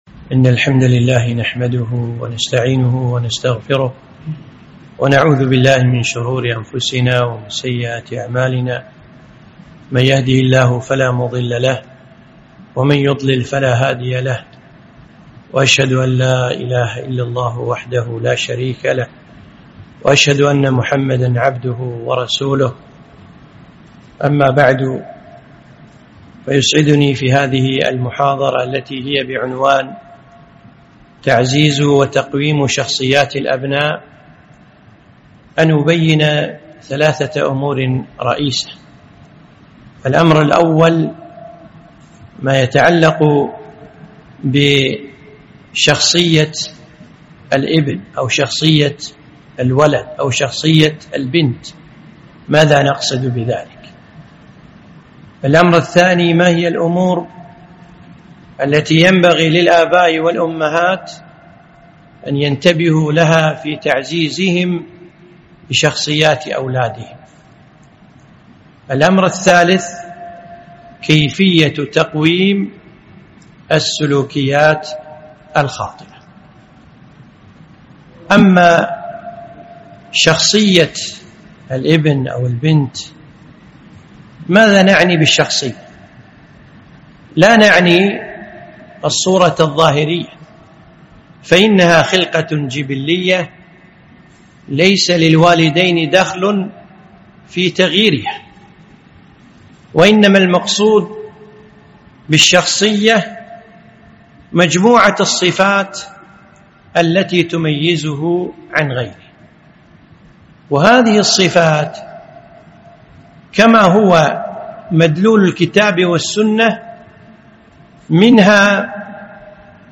محاضرة - تعزيز وتقويم شخصيات الأبناء